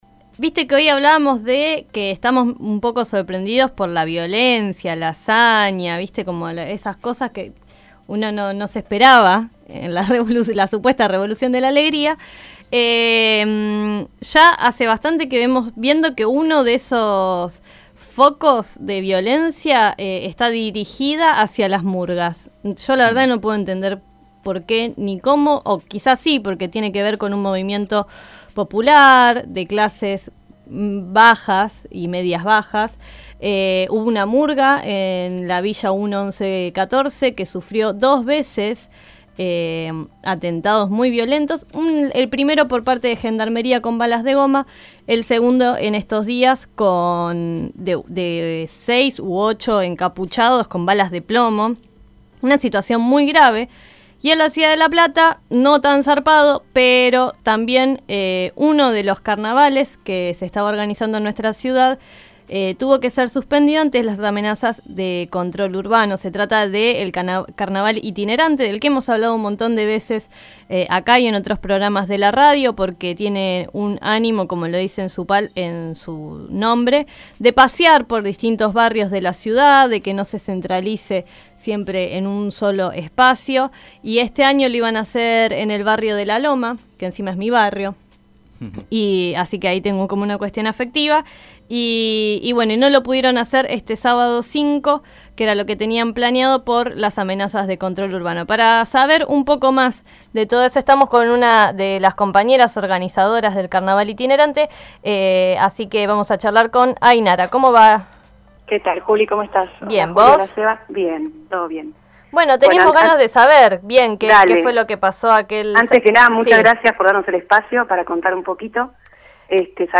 Tren Para Pocos dialogó con una de las integrantes del Carnaval Itinerante